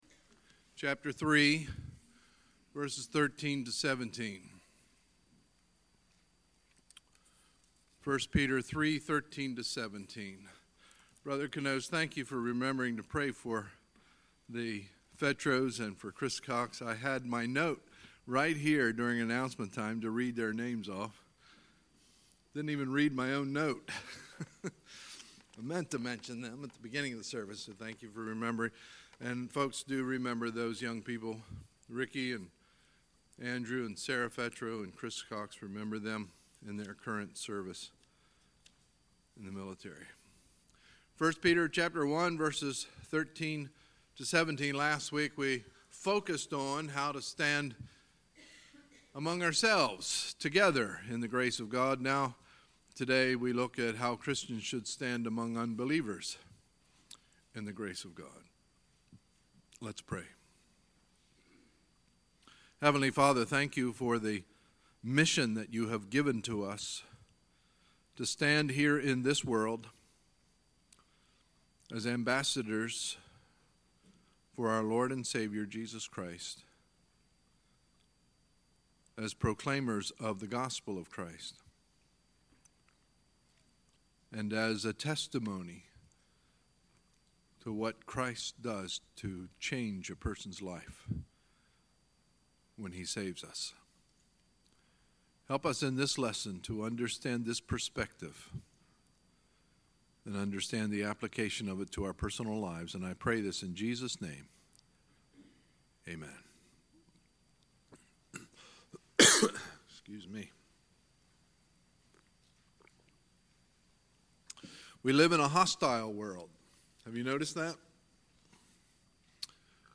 Sunday, May 27, 2018 – Sunday Morning Service